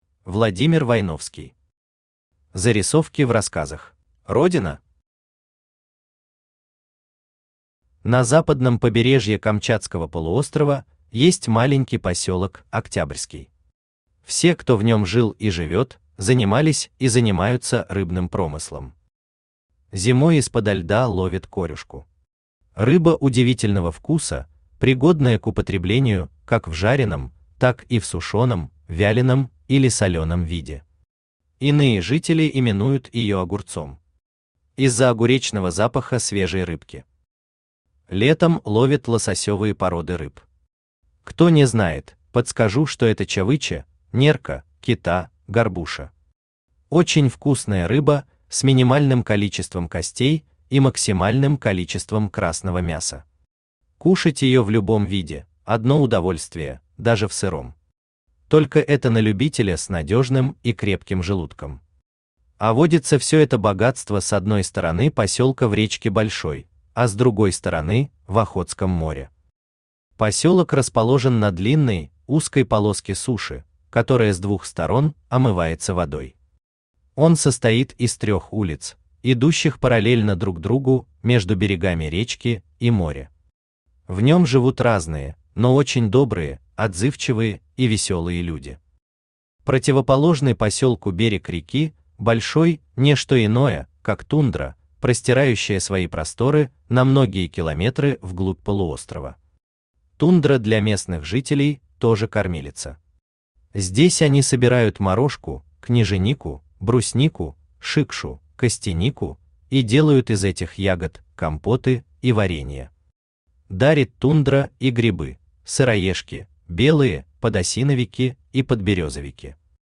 Аудиокнига Зарисовки в рассказах | Библиотека аудиокниг
Aудиокнига Зарисовки в рассказах Автор Владимир Войновский Читает аудиокнигу Авточтец ЛитРес.